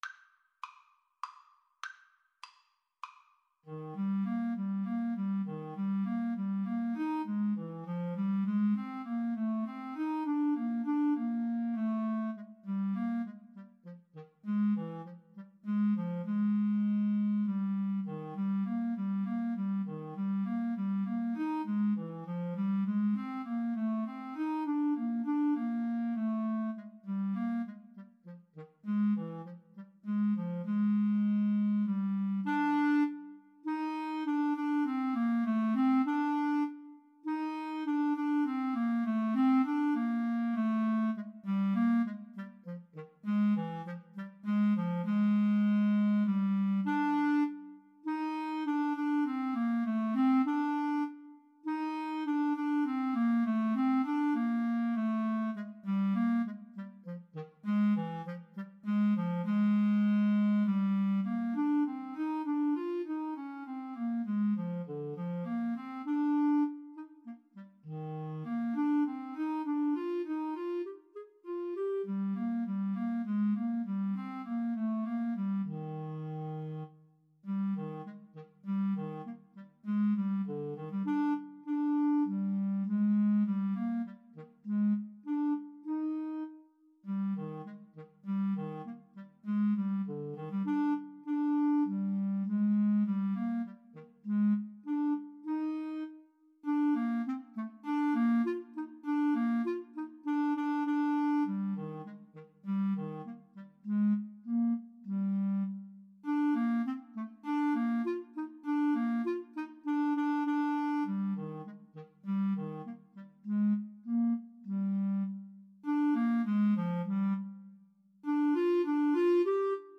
Eb major (Sounding Pitch) F major (Clarinet in Bb) (View more Eb major Music for Clarinet-Saxophone Duet )
3/4 (View more 3/4 Music)
Cantabile
Classical (View more Classical Clarinet-Saxophone Duet Music)